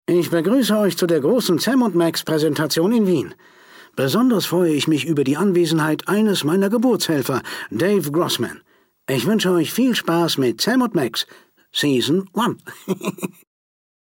Sam and Max Season 1 Sample 01 Datei herunterladen weitere Infos zum Spiel in unserer Spieleliste Beschreibung: Begrüßung durch Max.